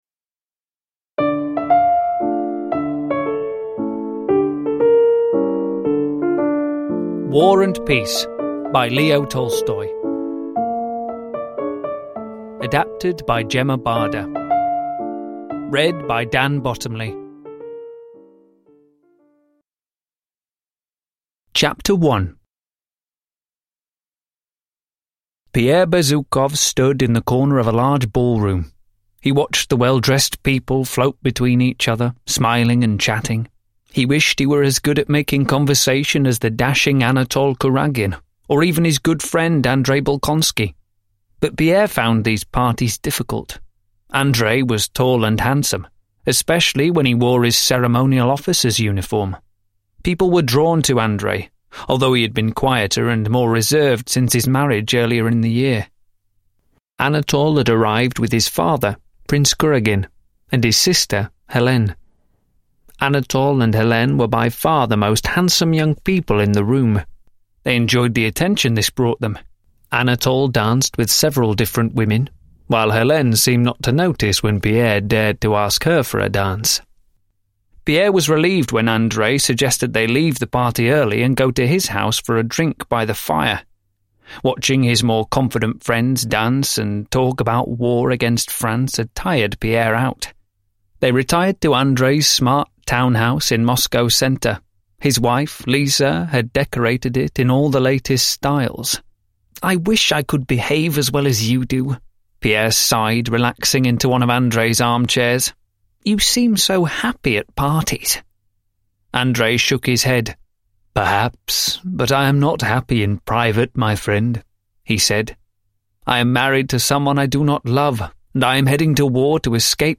War and Peace (lättläst) – Ljudbok